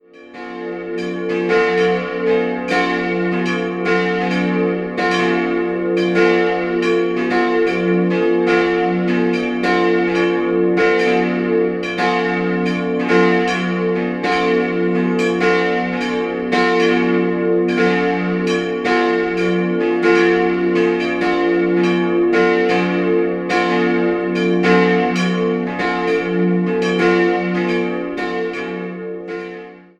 3-stimmiges Geläut: fis'-a'-d'' Die kleine Glocke wurde 1624 von David Fobbe in Göttingen gegossen, die mittlere 100 Jahre später von Anton Derling in Königsberg und die große ist ein Werk aus dem 13. Jahrhundert.